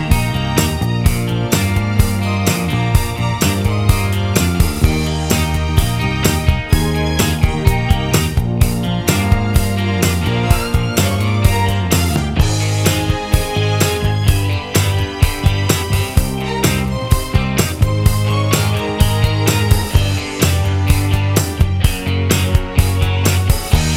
No Piano Pop (1970s) 5:19 Buy £1.50